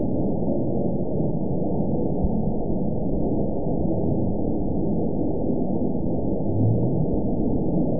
event 920924 date 04/16/24 time 06:39:48 GMT (1 year ago) score 9.11 location TSS-AB04 detected by nrw target species NRW annotations +NRW Spectrogram: Frequency (kHz) vs. Time (s) audio not available .wav